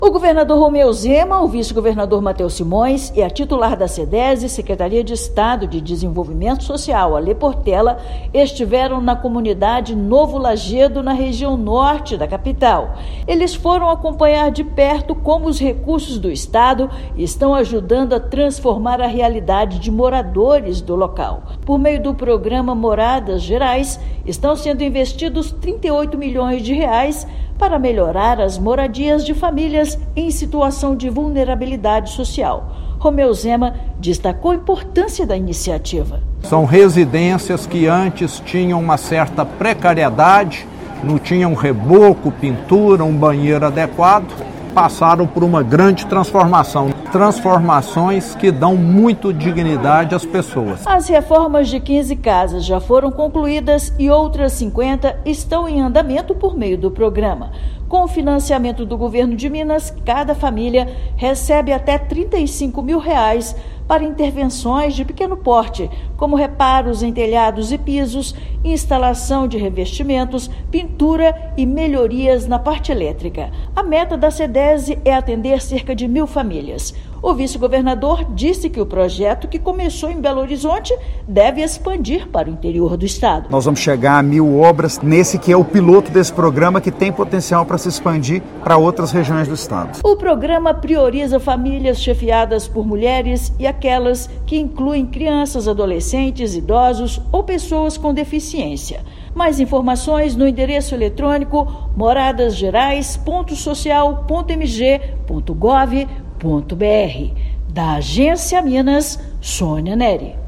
Iniciativa do Governo de Minas, por meio da Sedese, investe R$ 38 milhões em melhorias habitacionais. Ouça matéria de rádio.